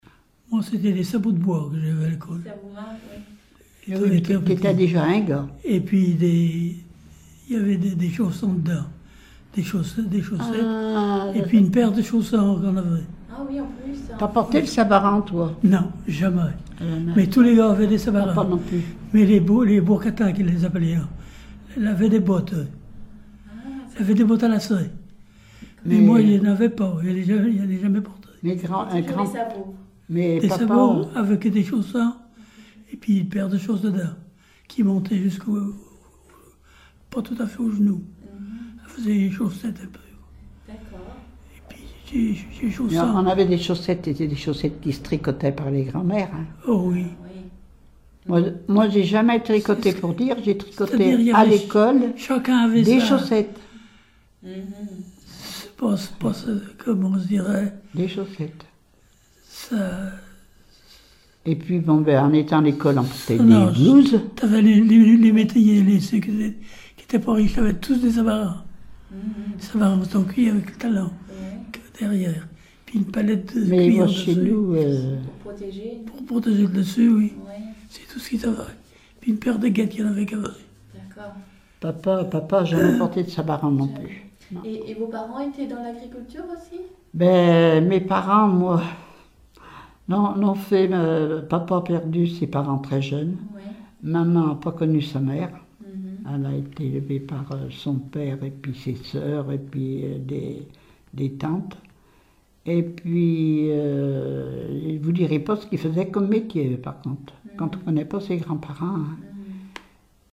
Témoignage sur la vie de l'interviewé(e)
Catégorie Témoignage